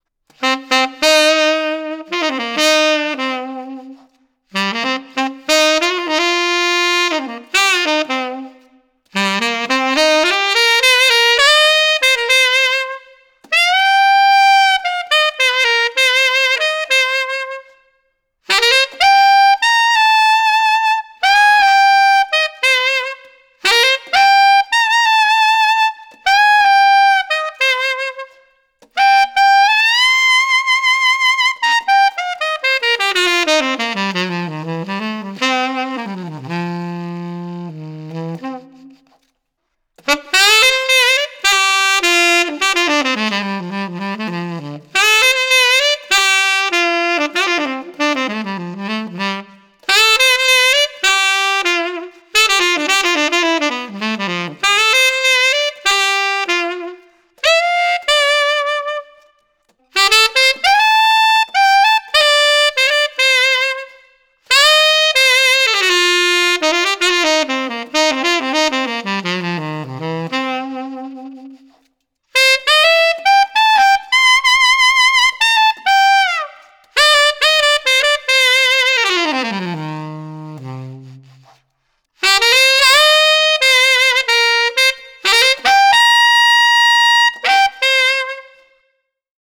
The third sound clip is of some more modern and funky sounding altissimo register playing with some reverb added.
Theo Wanne Brahma Gold Tenor Saxophone Mouthpiece – Rigotti Gold 2 1/2 Strong Reed-Altissimo Clip with Reverb Added